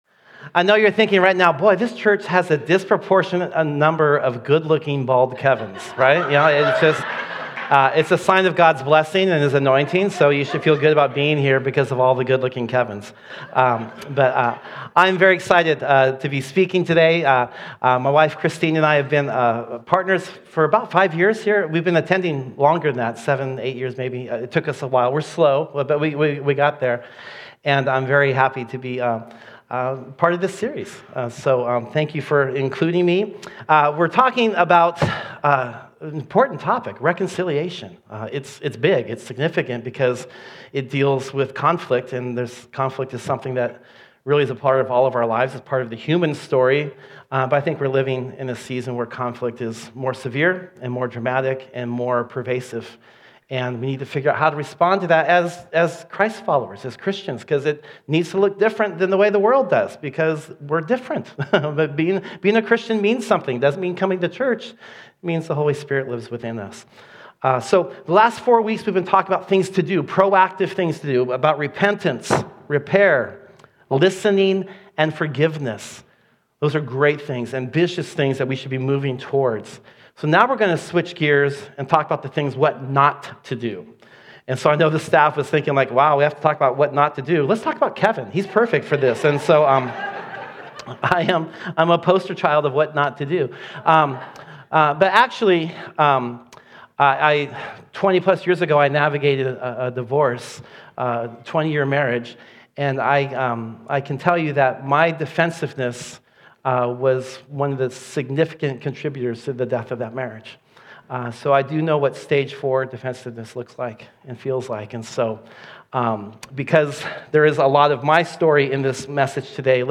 The River Church Community Sermons Defensiveness